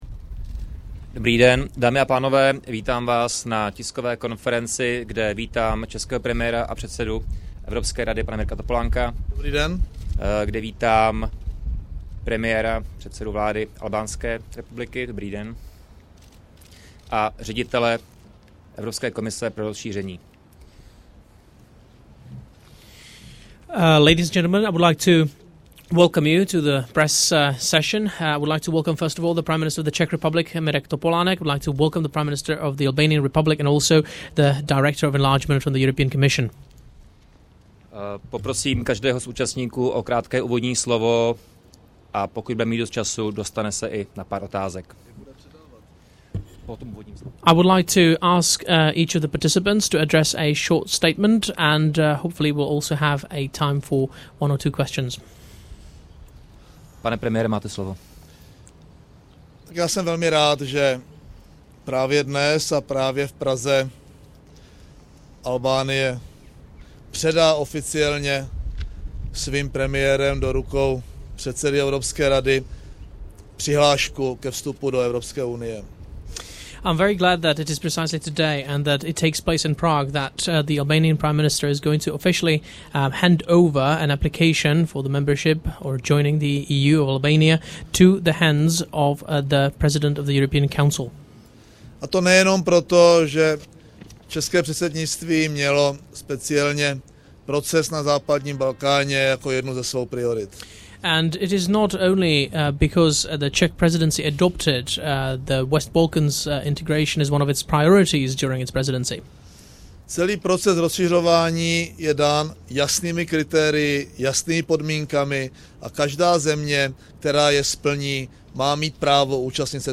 Další fotografie Zvuková příloha Tisková konference českého a albánského premiéra flv • 11724 kB